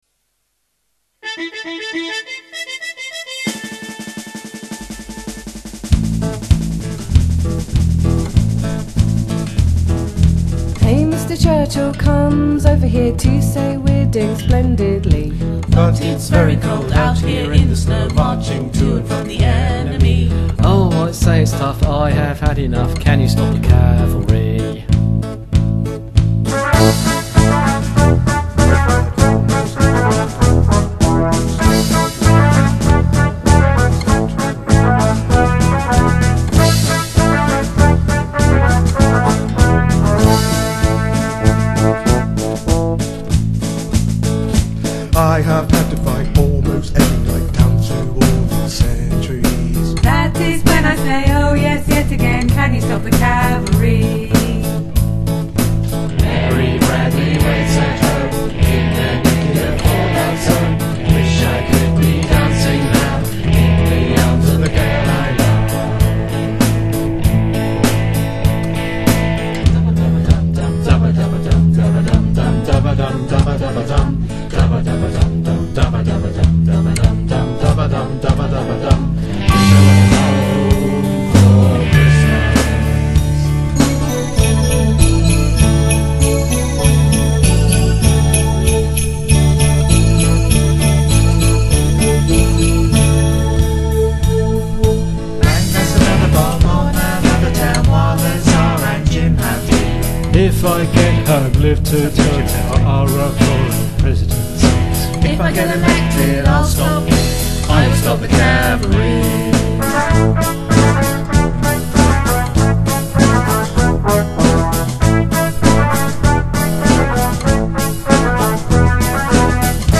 Bass
Drums/Guitar